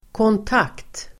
Uttal: [kånt'ak:t]